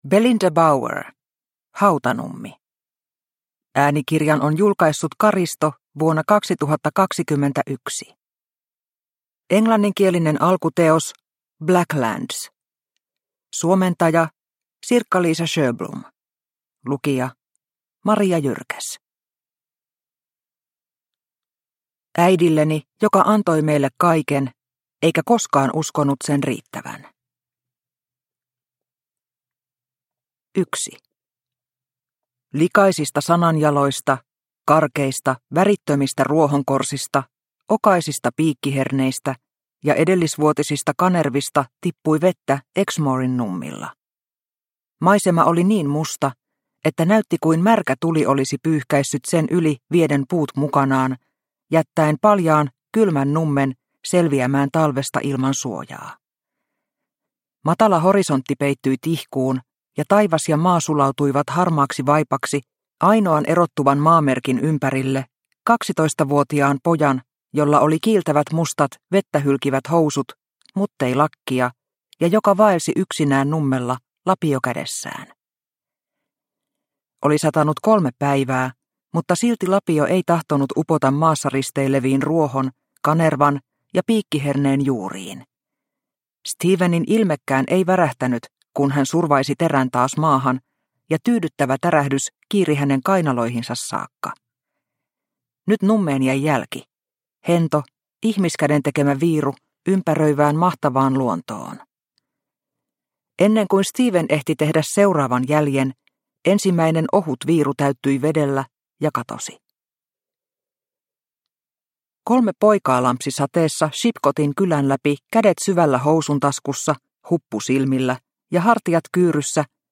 Hautanummi – Ljudbok – Laddas ner